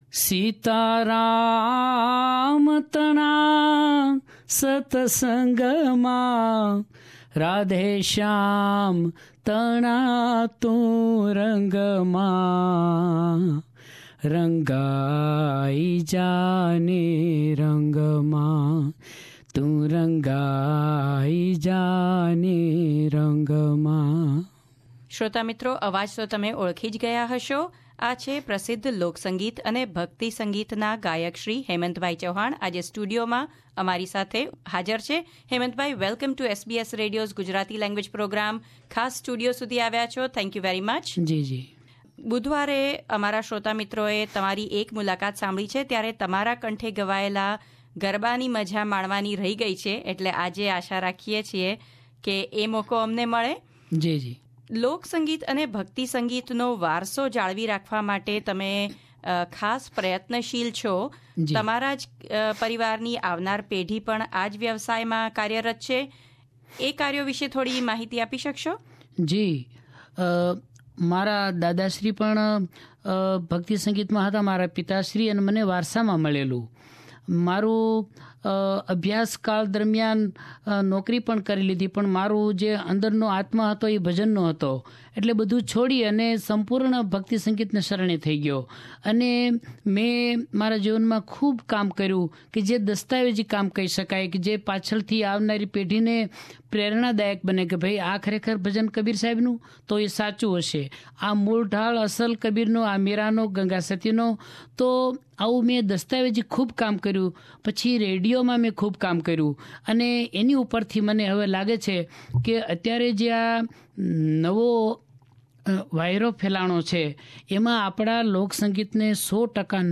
Shri Hemant Chauhan at SBS studio , Sydney